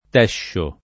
[ t $ ššo ]